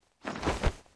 落地2zth070521.wav
通用动作/01人物/01移动状态/落地2zth070521.wav
• 声道 單聲道 (1ch)